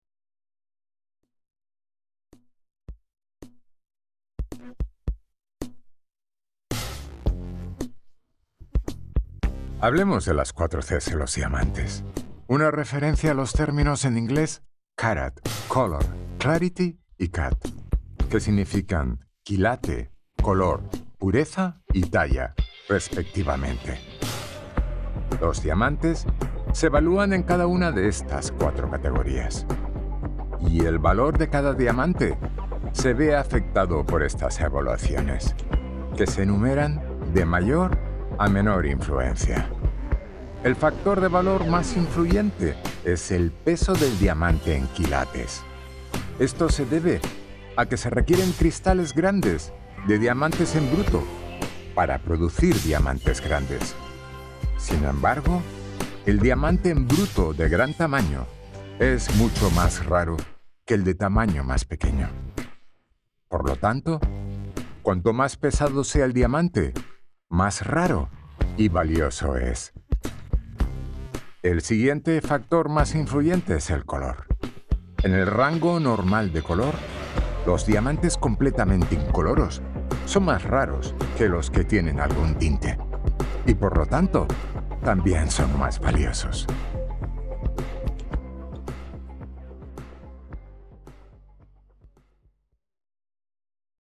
Spanish Castilian
Middle Aged
Senior